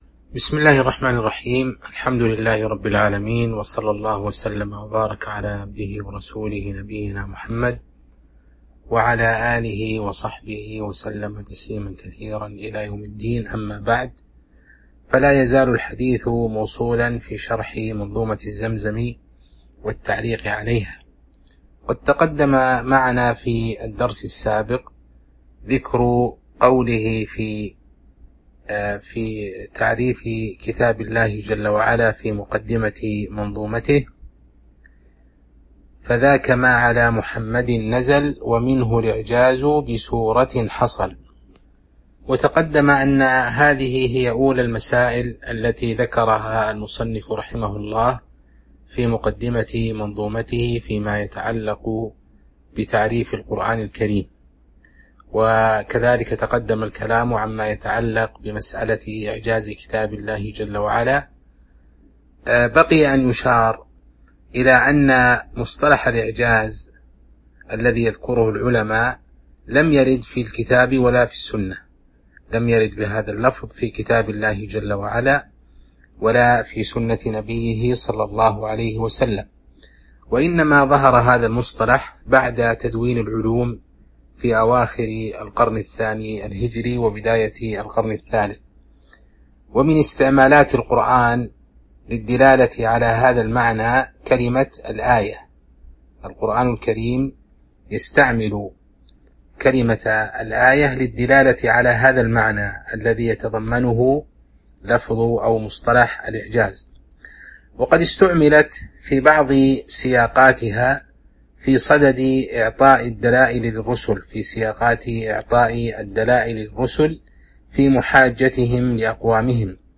تاريخ النشر ٢٣ ذو الحجة ١٤٤٢ هـ المكان: المسجد النبوي الشيخ